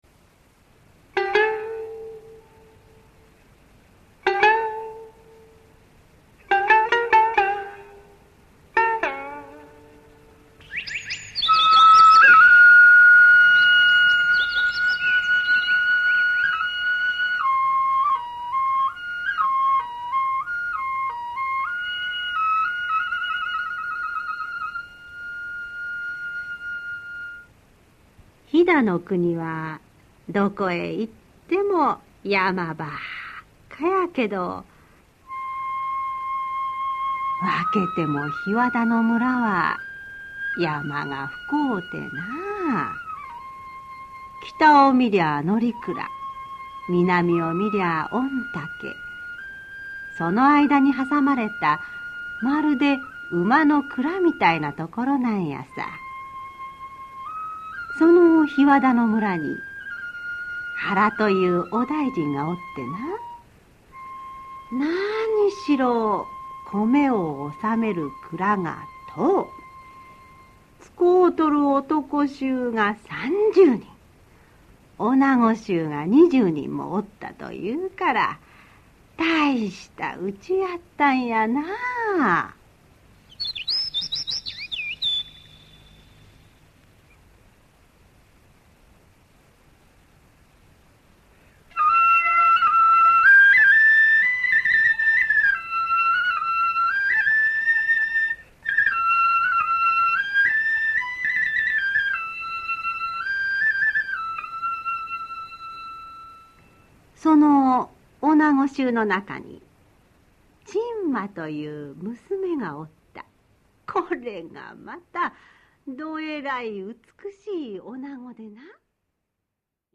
[オーディオブック] ちんまと小三郎